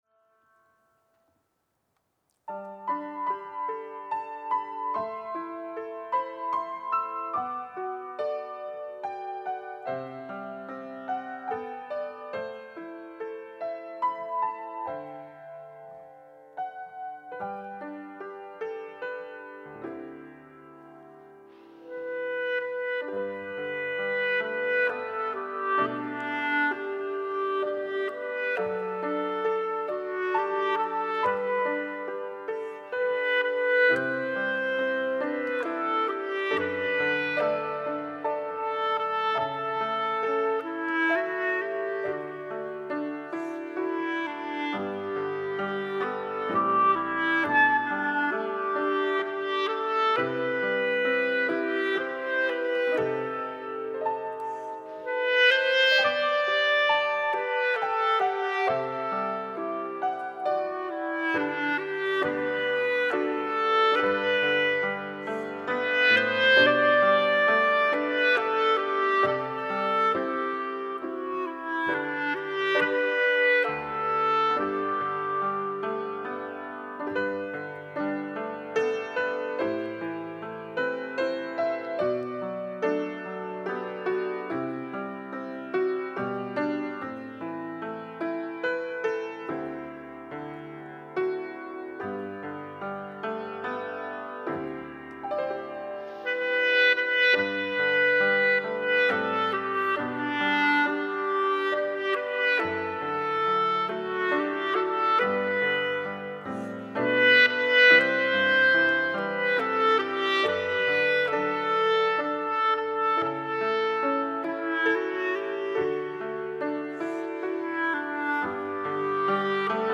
특송과 특주 - 나의 갈 길 다 가도록